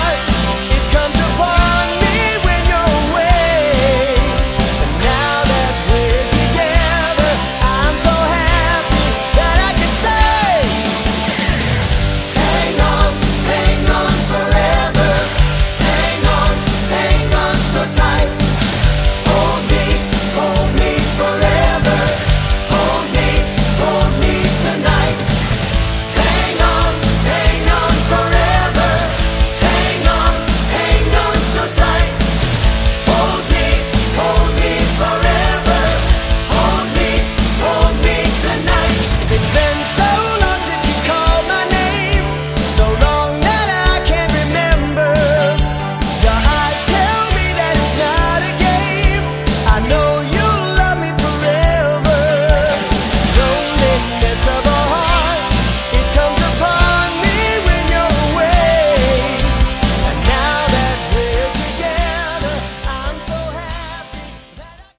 Category: AOR/melod hard rock
Vocals, Guitars
Vocals, Keyboards
Bass
Drums